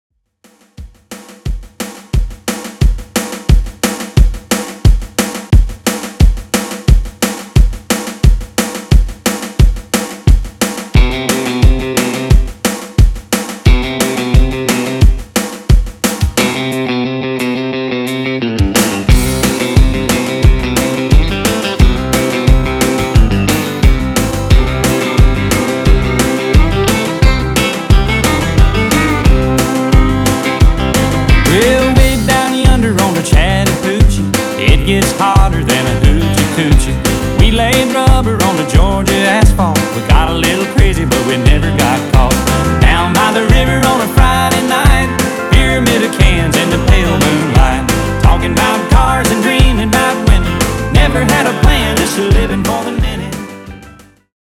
Genres: 90's , RE-DRUM Version: Clean BPM: 177 Time